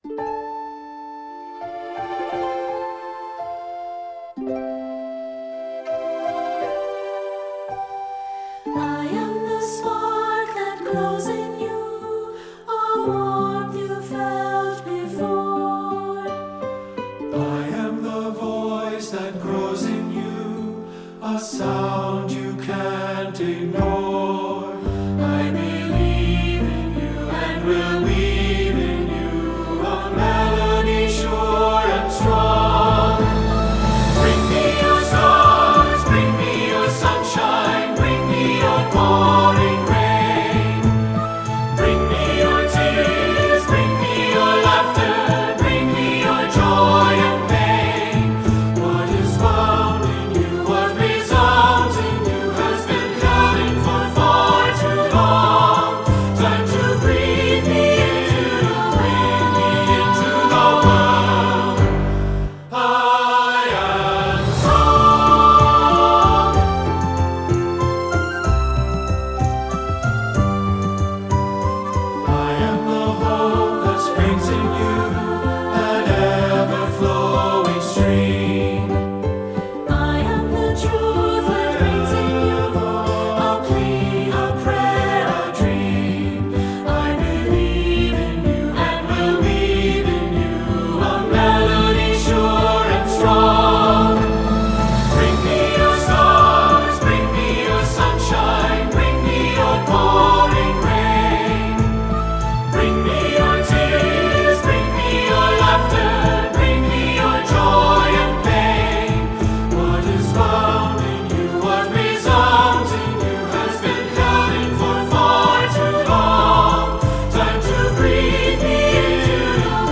Choral Concert/General
SATB